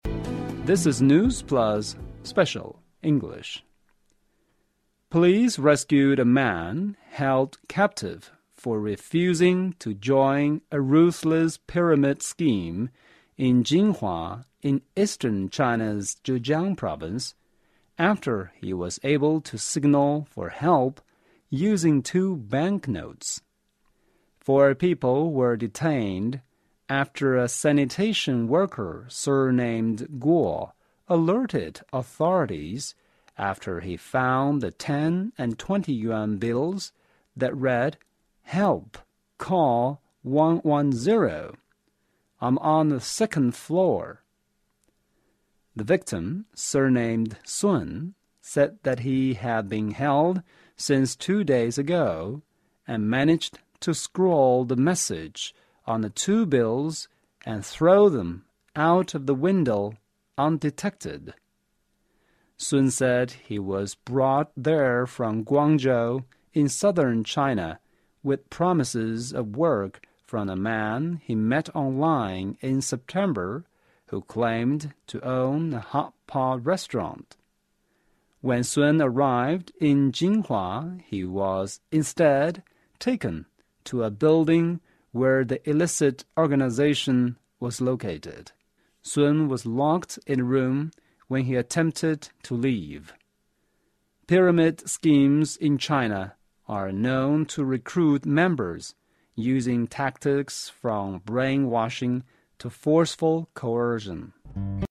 News Plus慢速英语:男子被骗到金华传销窝点 向环卫工人扔纸团求救